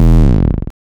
BWB 5 Sounds  Eletric Bass Slide.wav